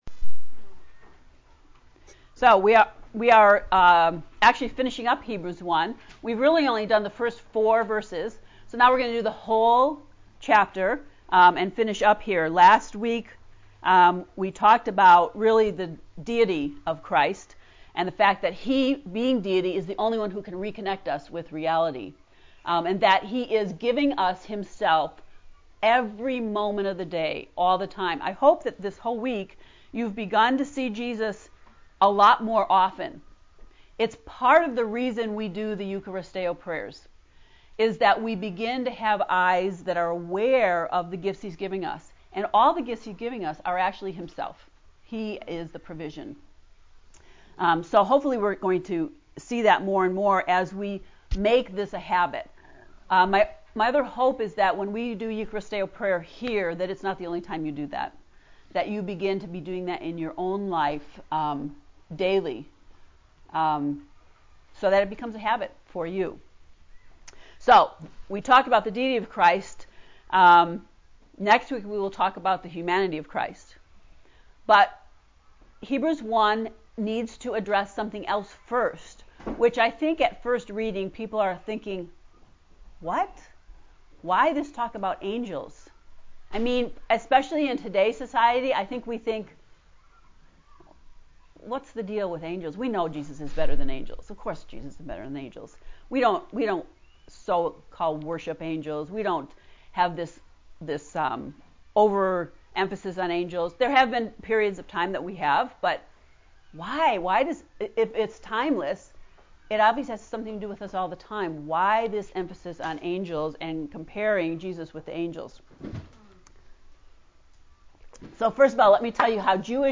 To listen to Lesson 5 lecture, “Better Than Angels”, click below:
heb-lecture-5.mp3